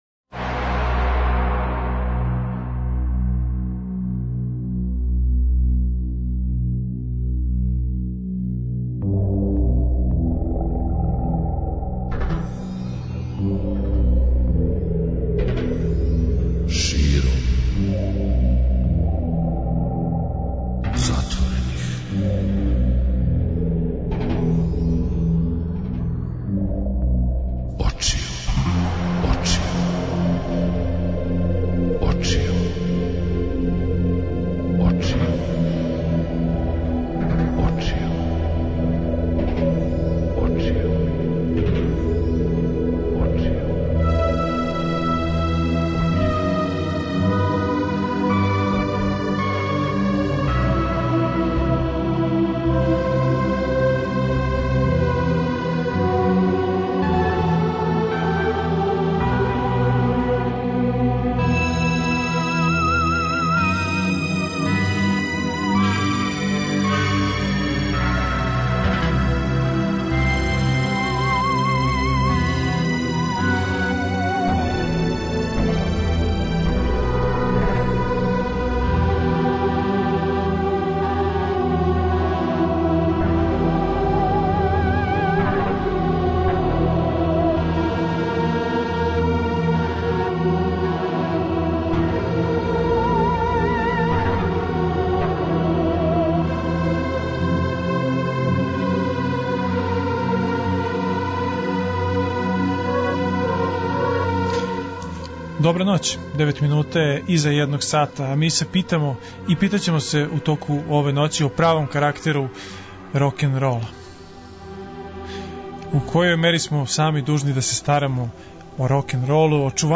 Надовезујући се на причу у студију, кроз контакт програм са слушаоцима говоримо о правом карактеру рокенрола, посебно наспрам културе кича. У којој мери смо сами дужни да се старамо о рокенролу, очувању те врсте побуне и њених вредности?